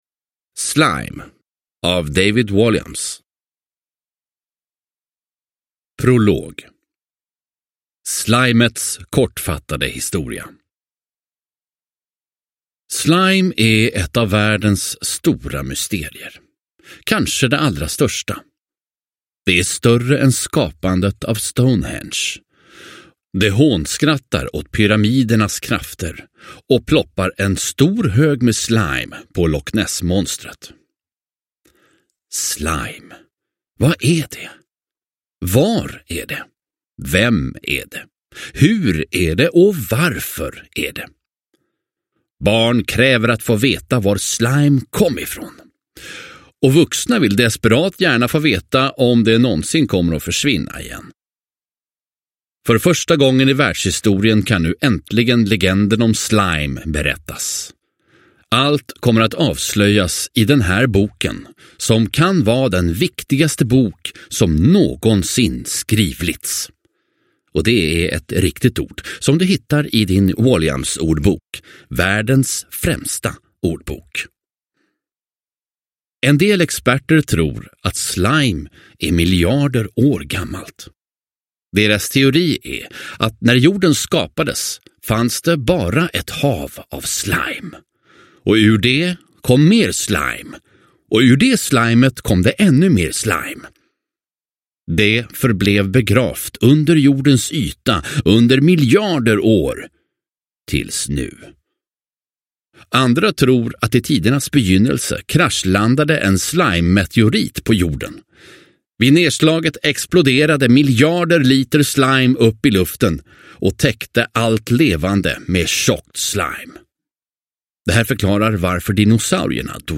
Slajm – Ljudbok – Laddas ner